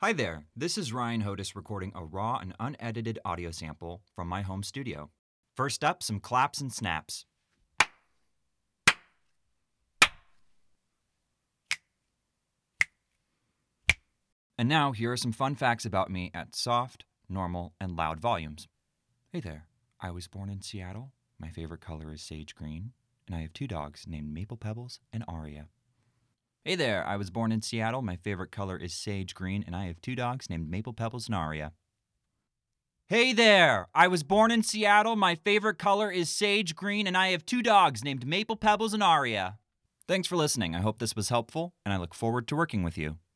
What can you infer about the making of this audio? RAW Studio Audio Sample My Source Connect Certified home studio includes a Rode Procaster Dynamic Broadcast Microphone, Focusrite Scarlet 2i2 USB Audio Interface + Cloud Cloudlifter CL-1 Mic Activator, Sound-proofed 4ft x 4ft standing recording booth